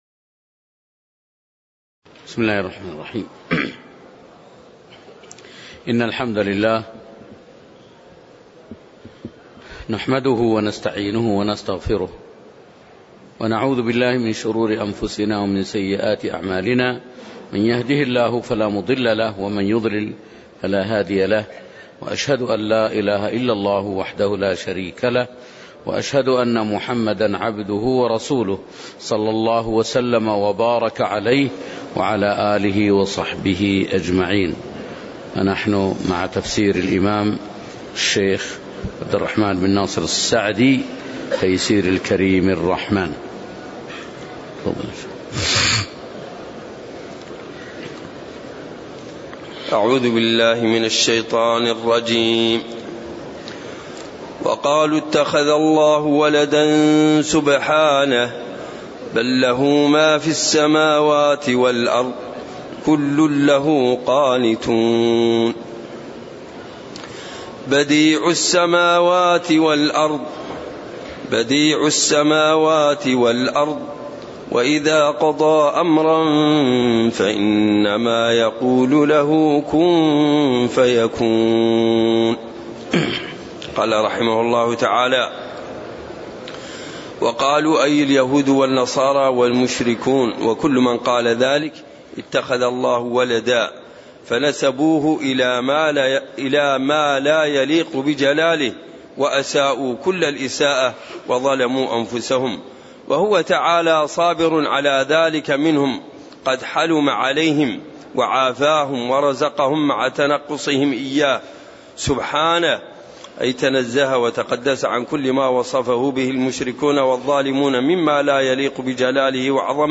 تاريخ النشر ١٦ ربيع الثاني ١٤٣٨ هـ المكان: المسجد النبوي الشيخ